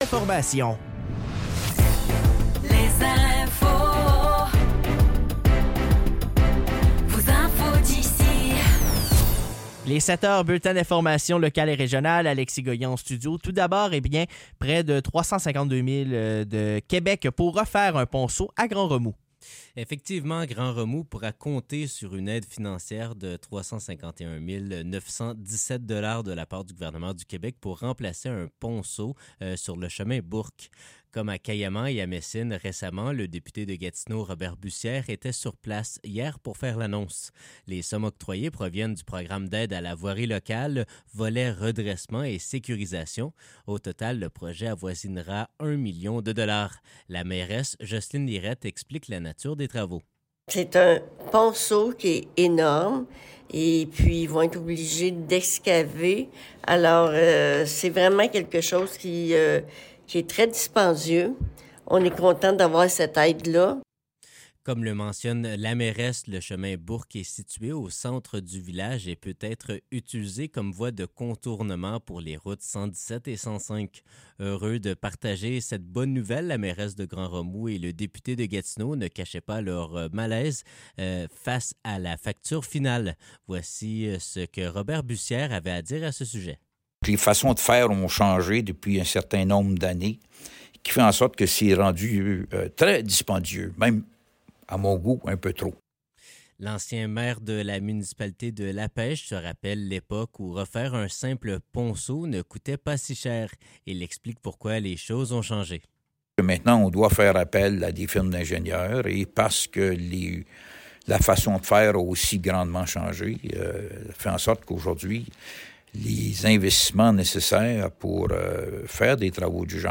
Nouvelles locales - 11 mars 2025 - 7 h